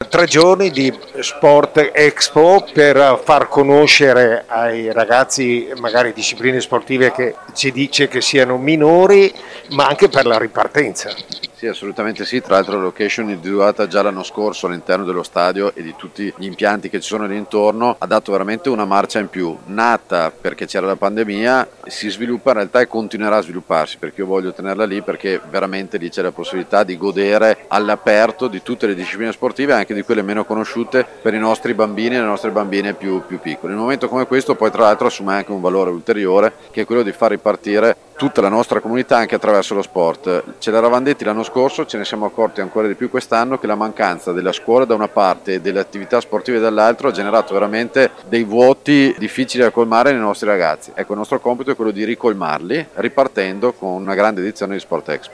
Il Sindaco di Verona Federico Sboarina: